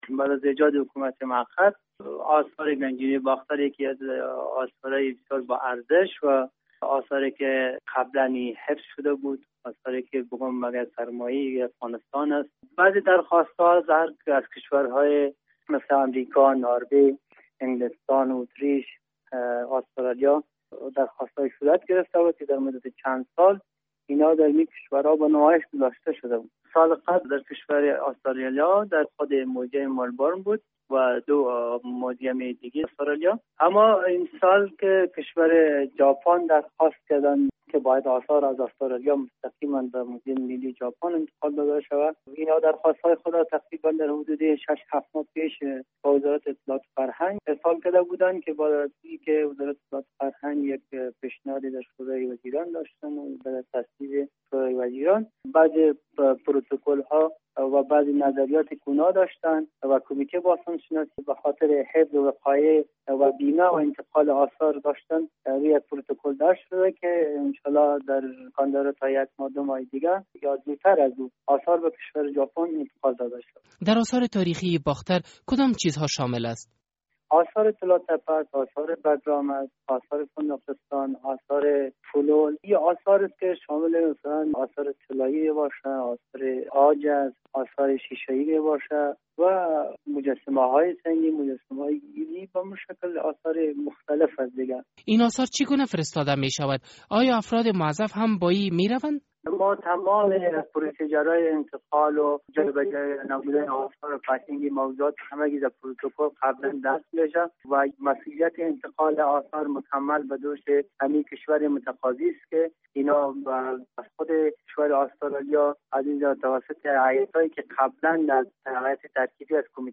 مصاحبه در مورد نماشگاه آثار باستانی افغانستان در جاپان